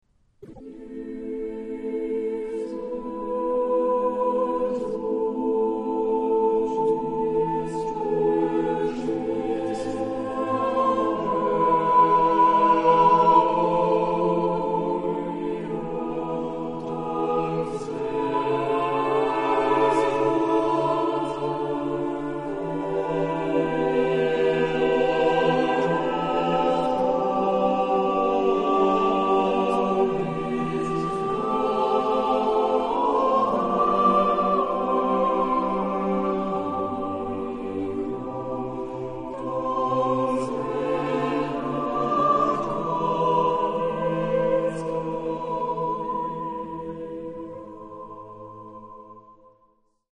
Genre-Style-Form: Sacred ; Renaissance ; Motet
Type of Choir: SATB  (4 mixed voices )
Tonality: A minor